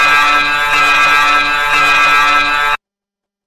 Security Alert
A pulsing security system alert with an electronic tone and flashing rhythm
security-alert.mp3